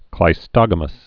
(klī-stŏgə-məs)